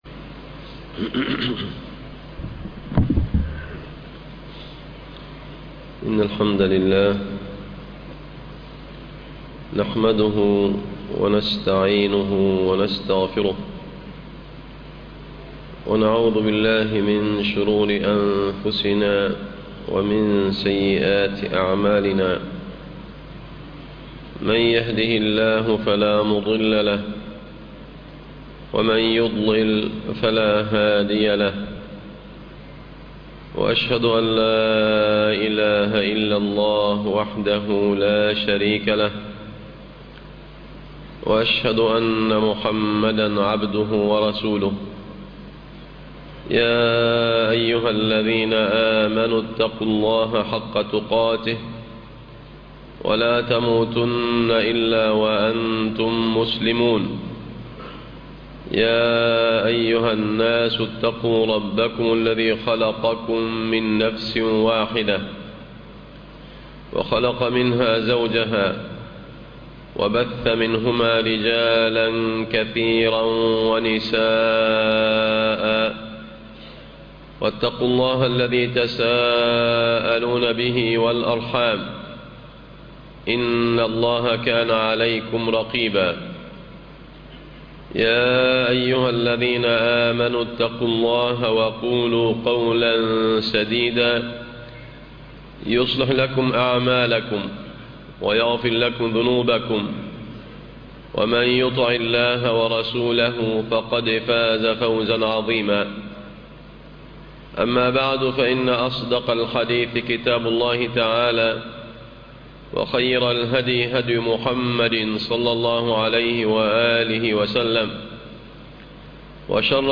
ذم البخل وفضل النفقة على الأهل والعيال ( خطب الجمعة